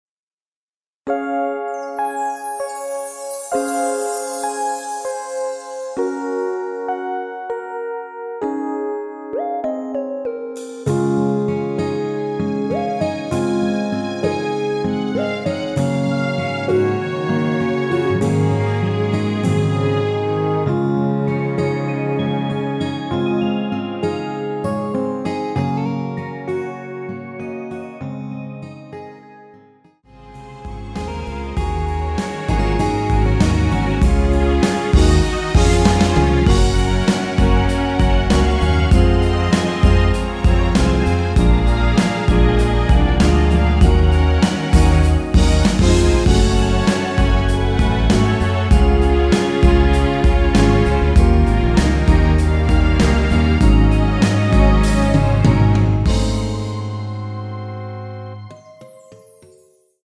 원키 멜로디 포함된 MR입니다.
Db
노래방에서 노래를 부르실때 노래 부분에 가이드 멜로디가 따라 나와서
앞부분30초, 뒷부분30초씩 편집해서 올려 드리고 있습니다.
중간에 음이 끈어지고 다시 나오는 이유는